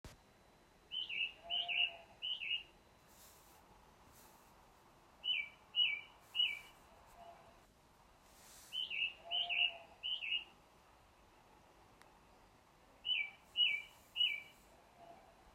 Fuglelyd
Går det an å indentifisere hvilken fugl dette er ? Stod på en topp i lang tid med denne "sangen", i størrelse kunne den se ut ca som en stær, men kom ikke nærme nok til å få sett hva slags fugl.
Høres ut som måltrost - kort strofe.